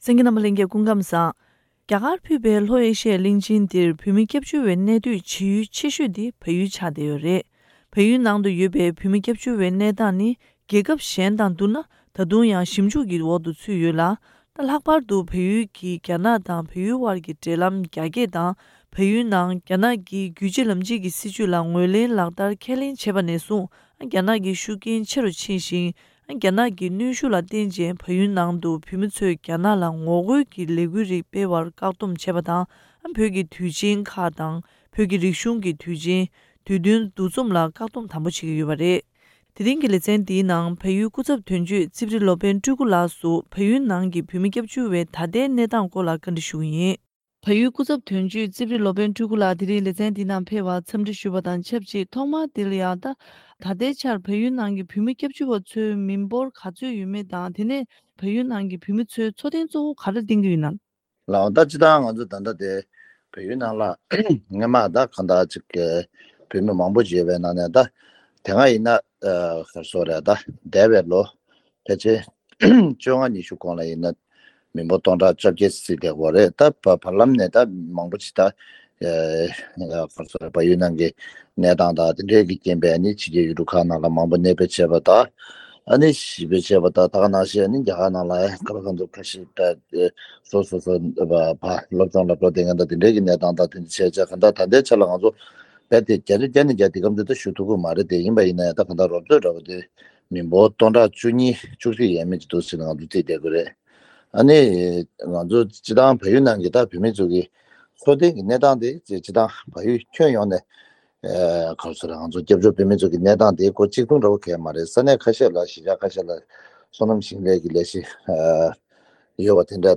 ཐེངས་འདིའི་བཅར་འདྲིའི་ལེ་ཚན